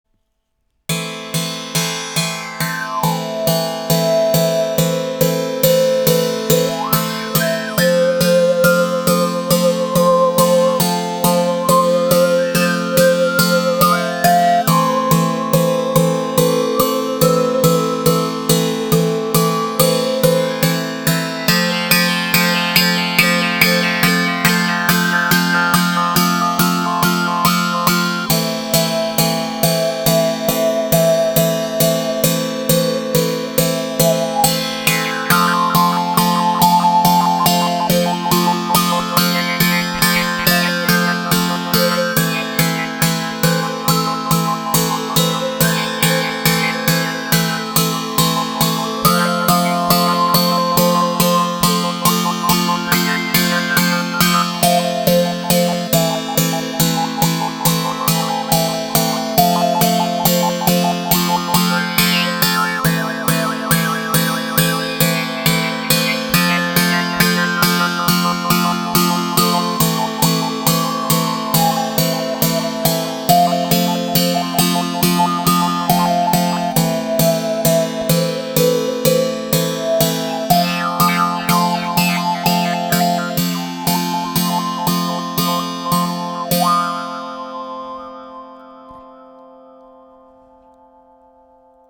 Le son est très chaud et attrayant…entrainant.
Les sons proposés ici sont réalisés sans effet.
La baguette sera utilisée pour percuter les cordes, l’une, l’autre ou toutes.